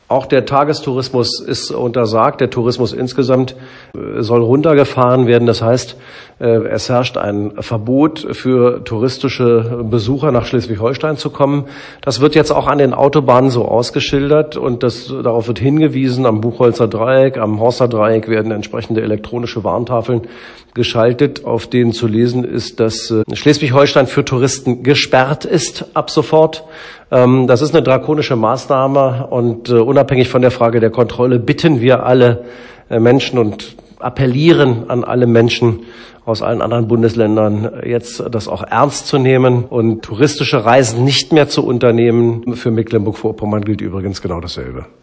Buchholz sagte dazu – Audio starten, Pfeil klicken…
buchholz_warnschilder.mp3